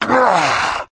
naga_warrior_damage.wav